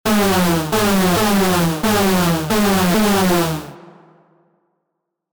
Otro esencial del sonido ‘Rave’, este ‘hoover’ es bastante similar en cuanto a timbre al ‘lead’ desafinado del ejemplo anterior.
Si escuchas atentamente el sonido de ejemplo, el ‘hoover’ no toca una nota constante que se mantenga en la misma tonalidad, si no que su tono sube al principio y cae según suena la nota.
Audio-4-How-to-make-classic-rave-sounds-on-Ableton-Live-.mp3